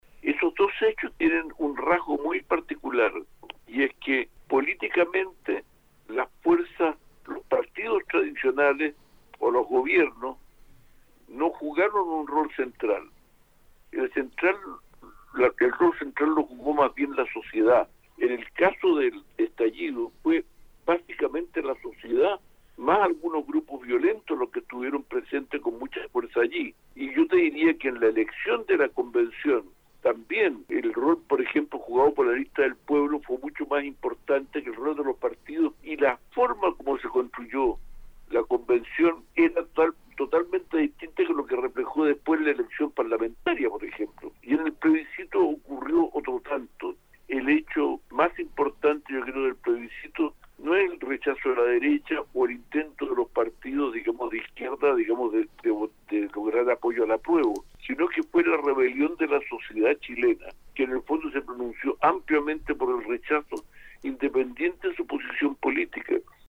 En entrevista con Radio UdeC, el ex subsecretario de Economía del gobierno de Salvador Allende indicó que “los dos hechos políticamente históricos de los últimos tres años son el estallido o revuelta de octubre de 2019 y el plebiscito de septiembre de este año”.